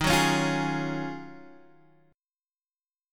Ebm11 chord